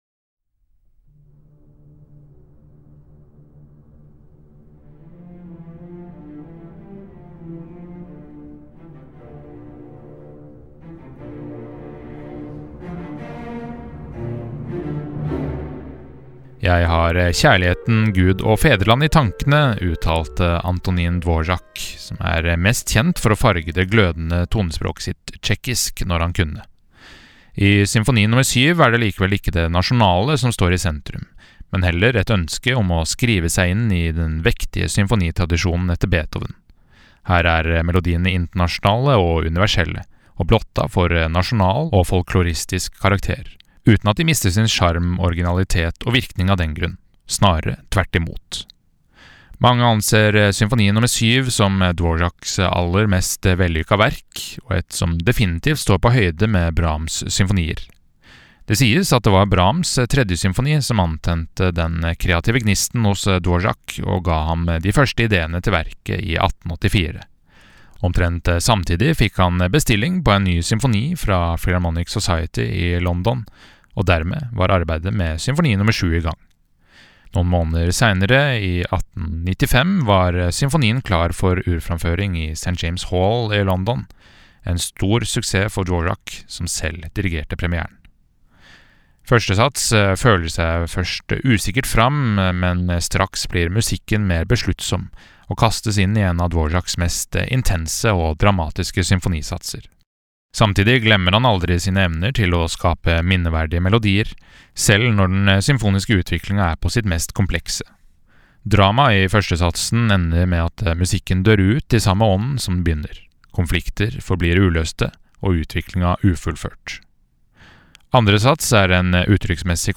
VERKOMTALE-Antonin-Dvoraks-Symfoni-nr.-7.mp3